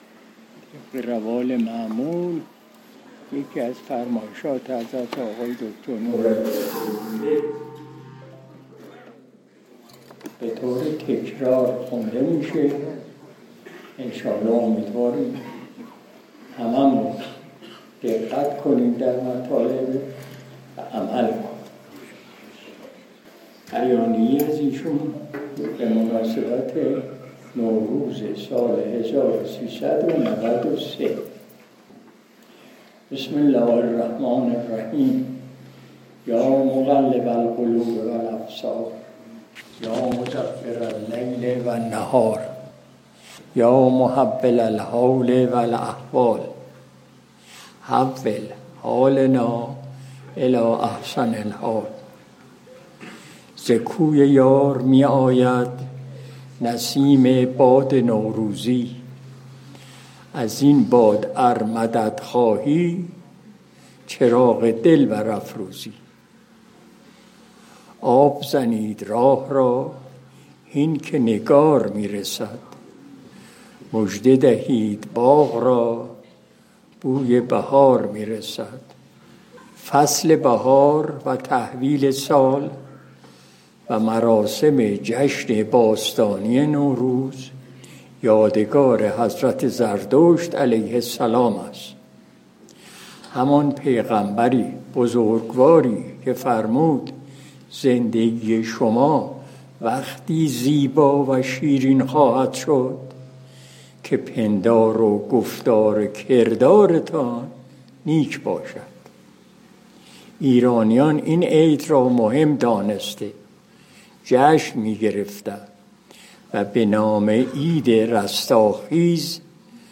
مجلس شب دوشنبه ۱۶ بهمن ماه ۱۴۰۱ شمسی – حسینیه مزار متبرک سلطانی بیدخت